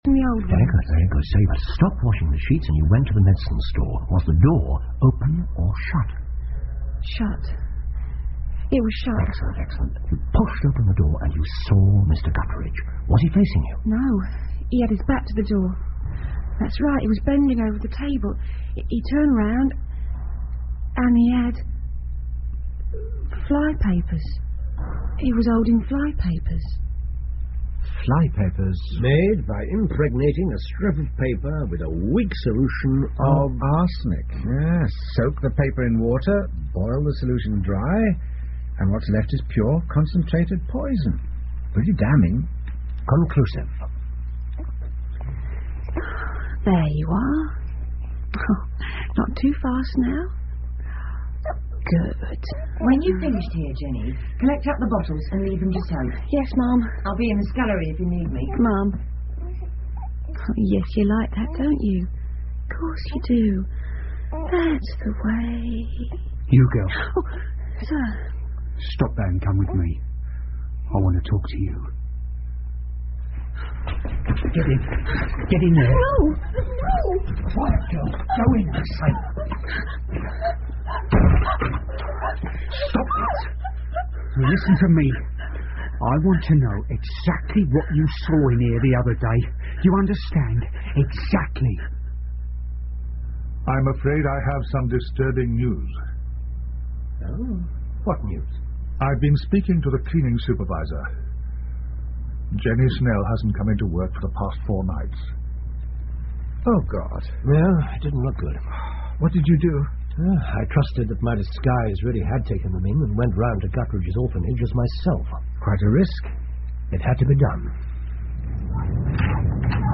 福尔摩斯广播剧 The Saviour Of Cripplegate Square 7 听力文件下载—在线英语听力室